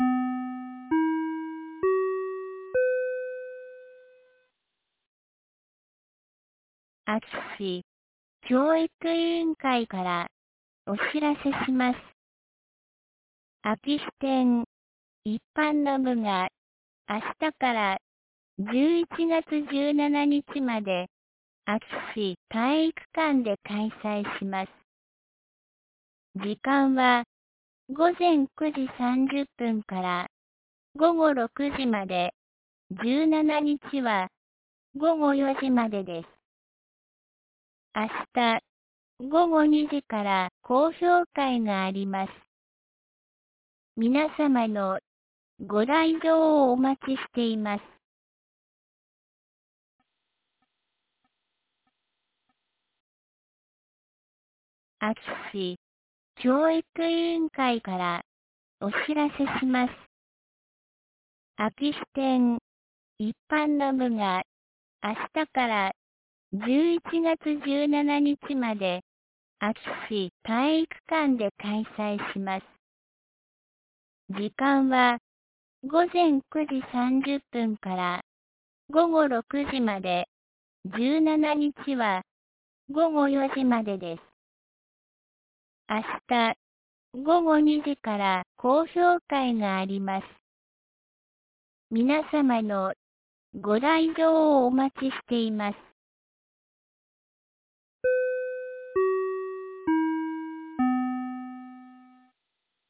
2024年11月08日 17時11分に、安芸市より全地区へ放送がありました。